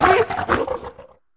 Cri de Crocogril dans Pokémon HOME.